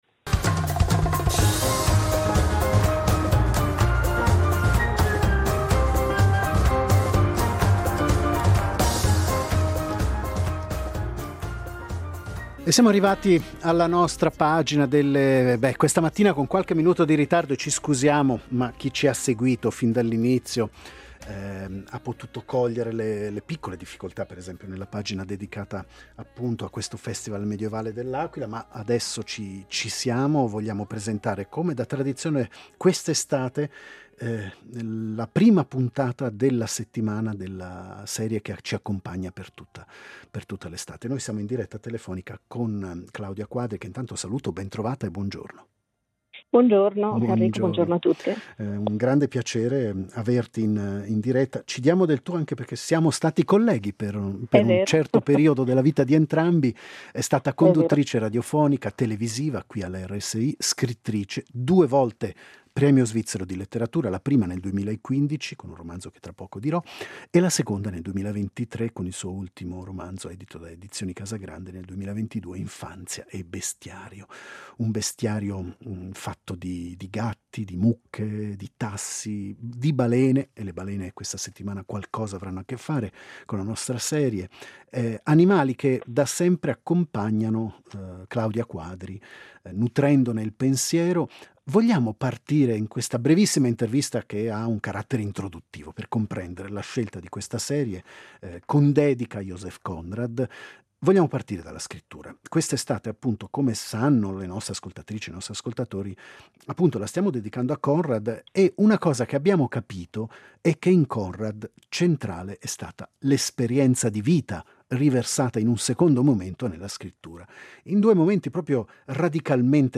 Intervista alla giornalista, scrittrice e regista